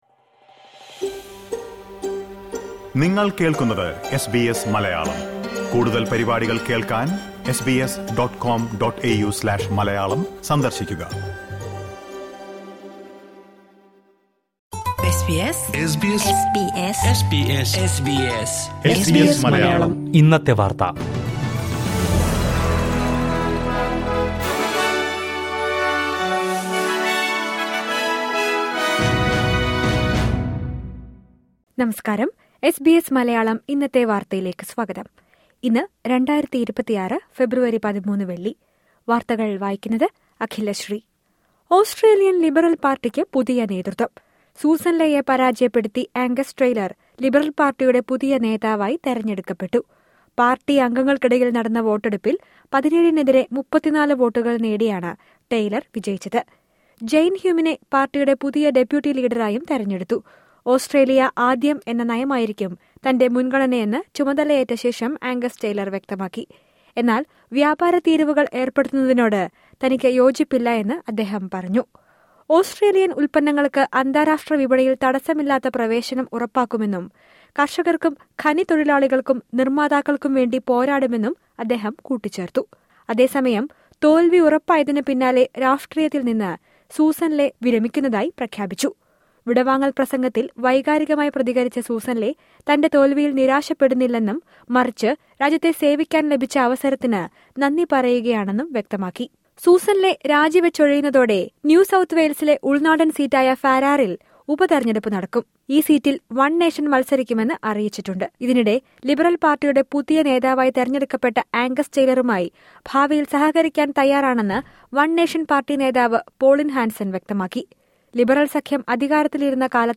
ഇന്നത്തെ വാർത്ത:ആംഗസ് ടെയ്ലർ പ്രതിപക്ഷ നേതാവായി;രാഷ്ട്രീയമവസാനിപ്പിച്ച് സൂസൻ ലേ